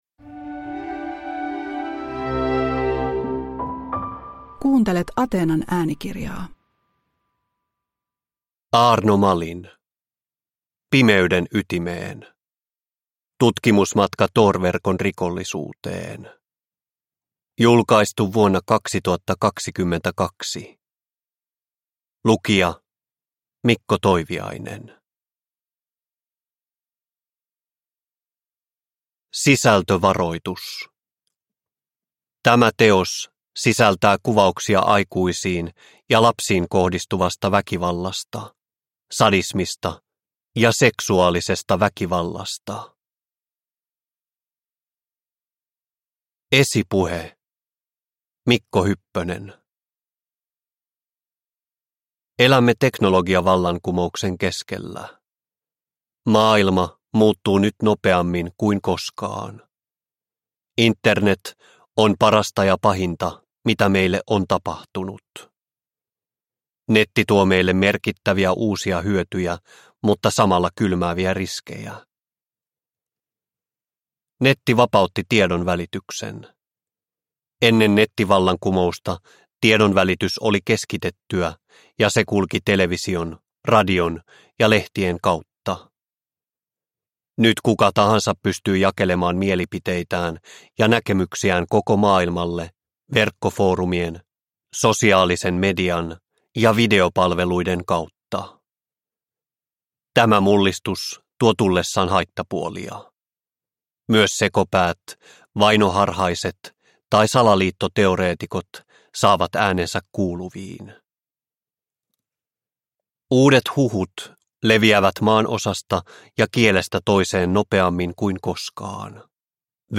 Pimeyden ytimeen – Ljudbok – Laddas ner